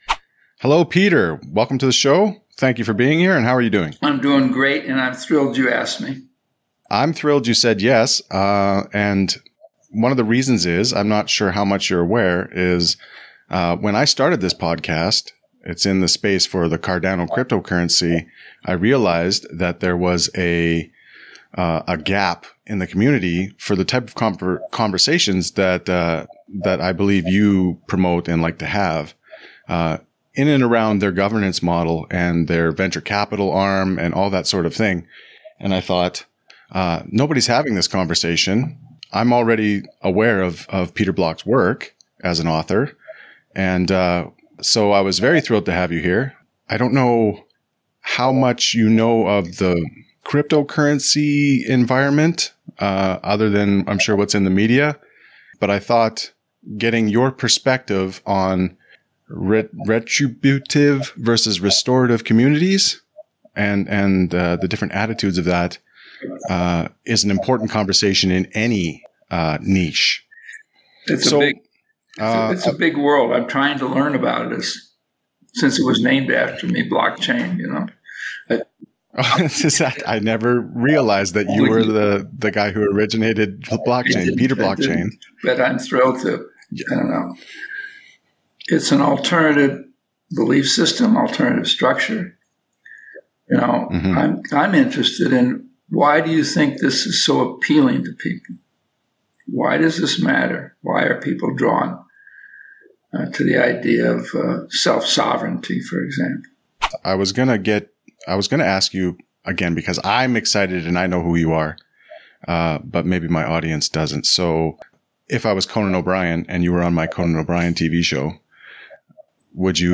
“Cardano Citizen” Interview